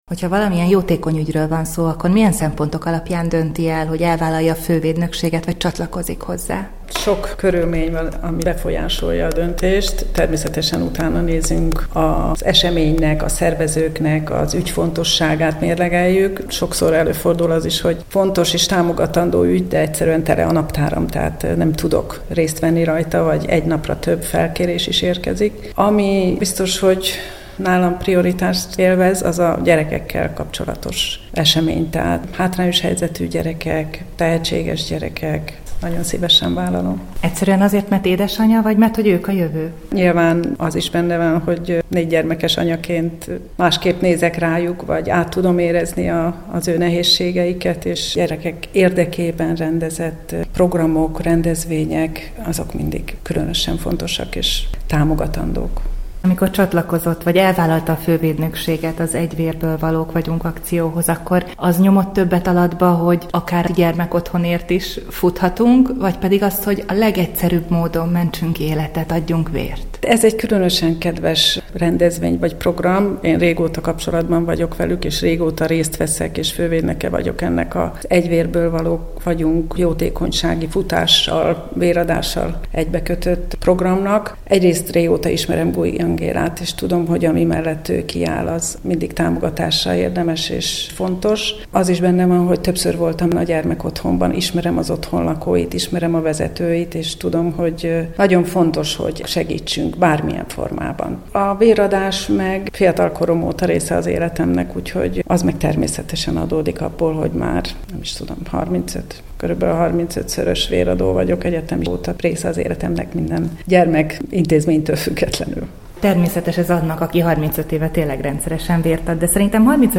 Beszélgetés az „Egy vérből valók vagyunk” jótékonysági futás fővédnökségéről a Kossuth Rádió Vasárnapi újság című műsorában